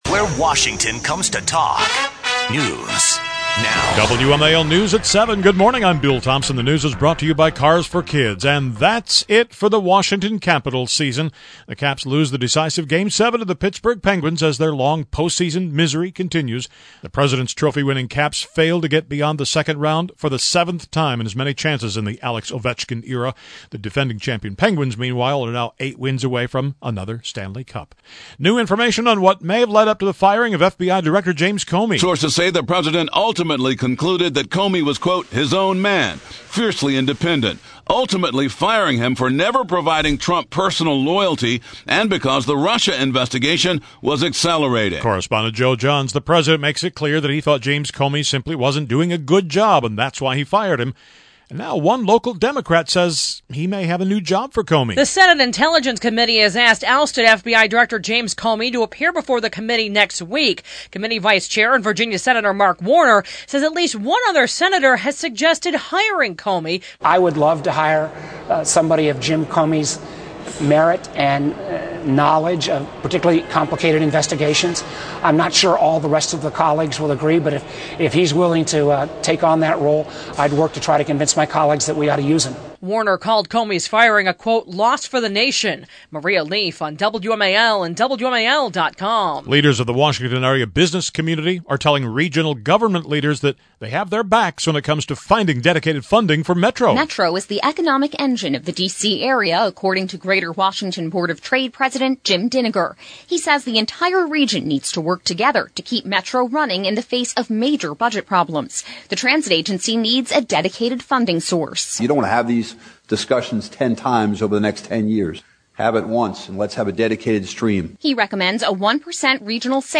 WMAL News nominates its 7 am newscast of Thursday, May 11, 2017 as Best Newscast in the 2018 Chesapeake Associated Press awards competition.